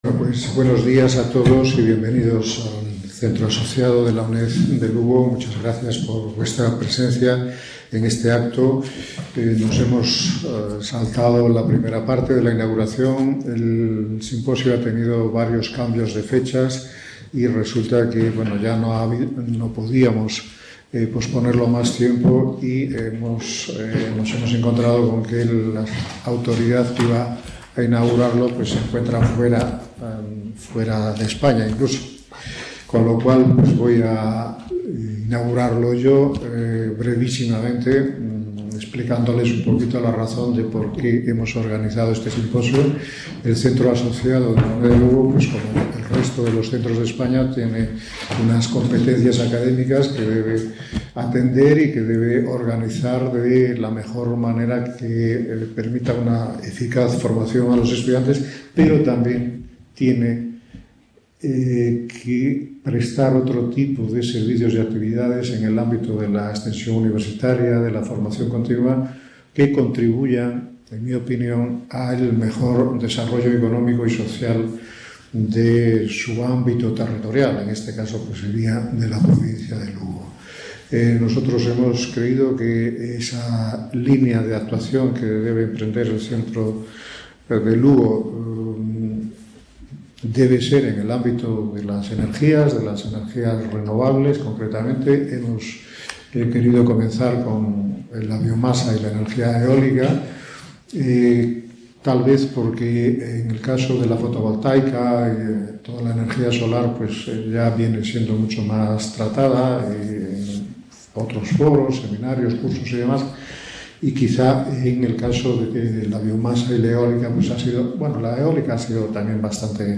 Recursos energéticos de la biomasa y del viento - Inauguración simposio
| Red: UNED | Centro: UNED | Asig: Reunion, debate, coloquio... | Tit: CONFERENCIAS | Autor:varios